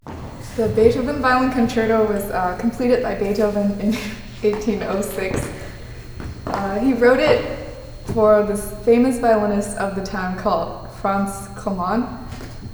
CLASS RECITAL
piano Tuesday
2013 5:00 p.m. Lillian H. Duncan Recital Hall
Recording of performance is incomplete.